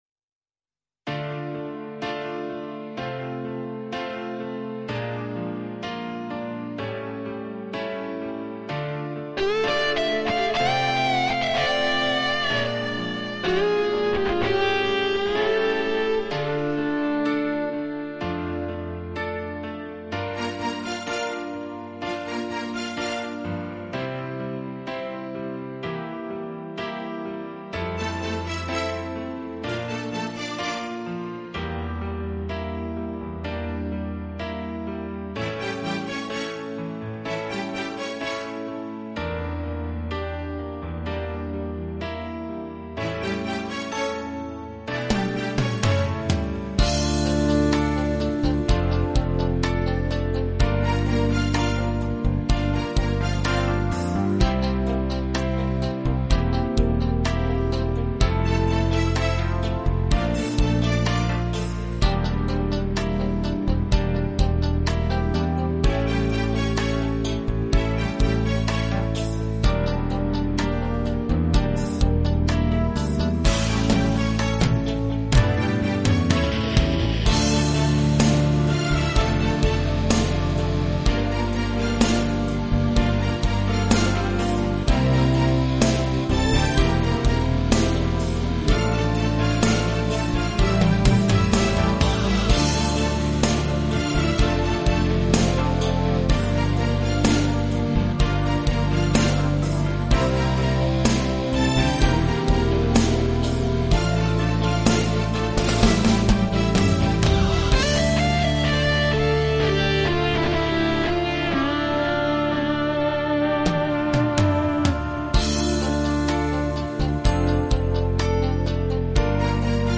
инструментальная версия